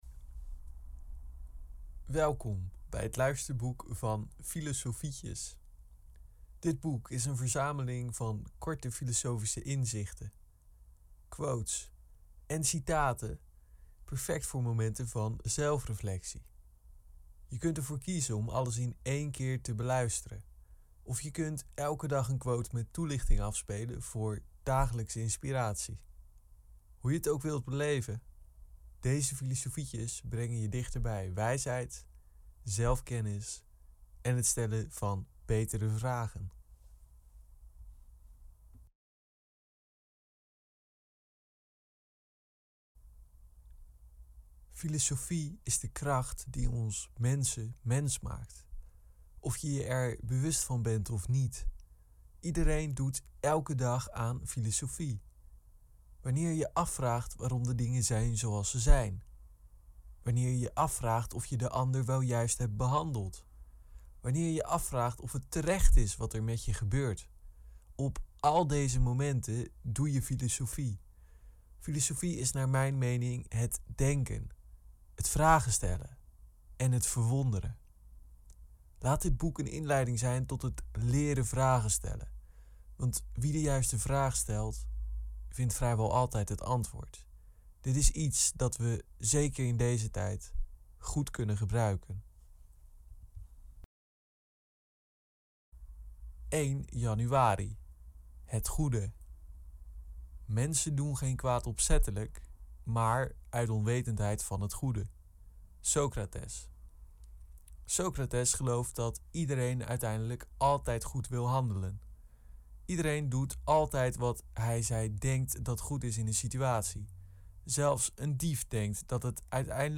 Filosofietjes - Luisterboek